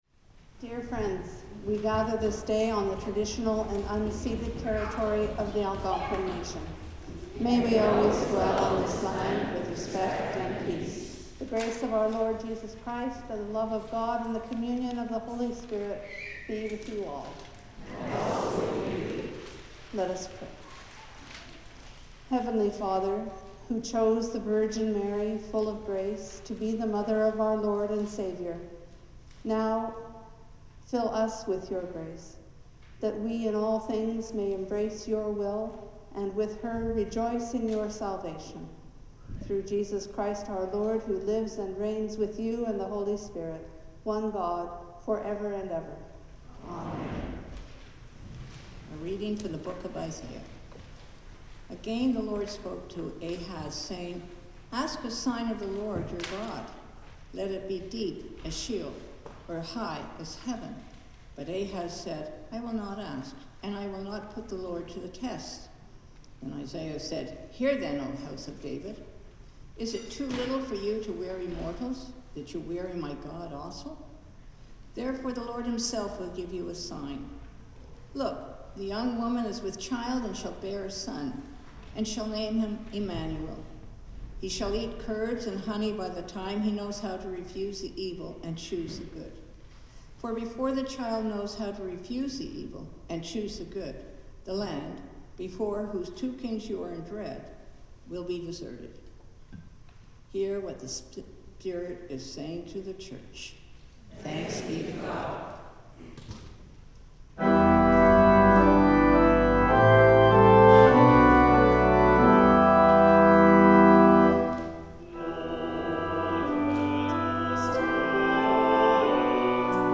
Sermon
The Lord’s Prayer (sung)
Hymn 91